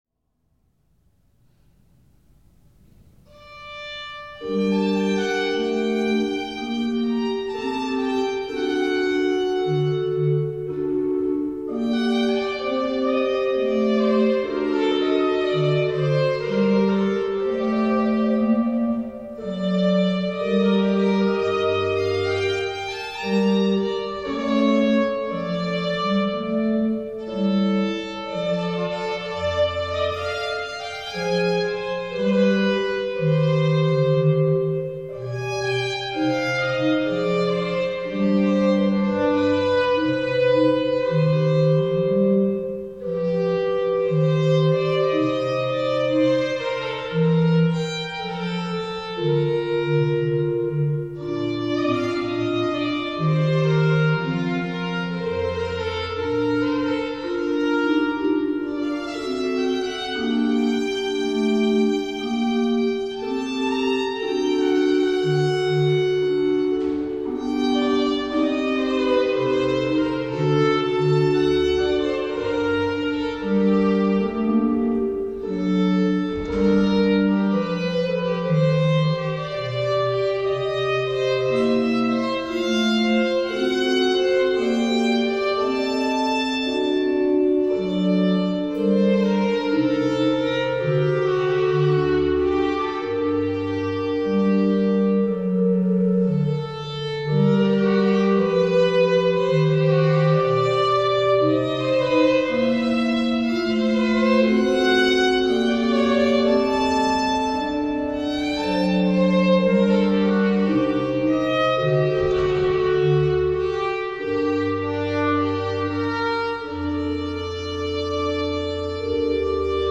Der Impuls für den Tag am Samstag wird immer ein kirchenmusikalischer Gruß sein, der nach Möglichkeit in einer unserer Kirchen aufgenommen wurde.
Die heutigen Aufnahmen, mit Musik für Violine und Orgel, aufgenommen am 20.3.2020, stammen aus St. Josef.
Adagio-Vivace-Grave-Allegro
Telemann-Sonate-Violine-und-Orgel-in-g.mp3